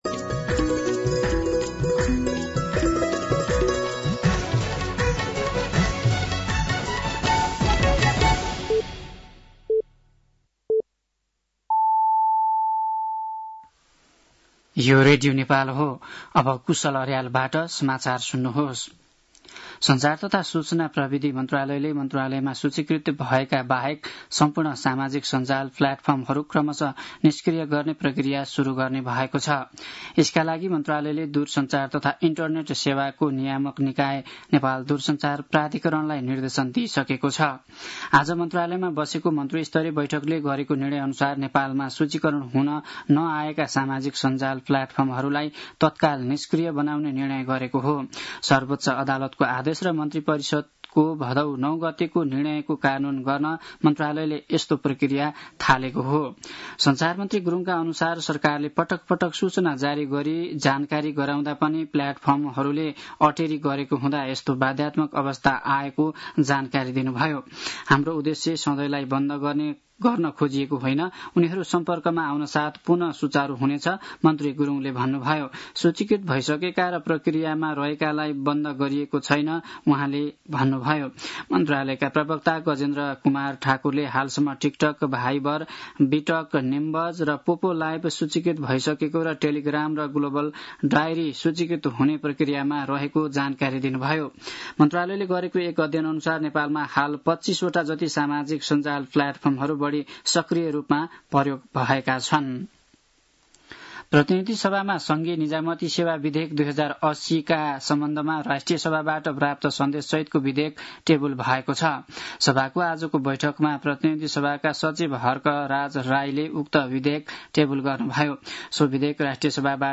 साँझ ५ बजेको नेपाली समाचार : १९ भदौ , २०८२
5.-pm-nepali-news-1-1.mp3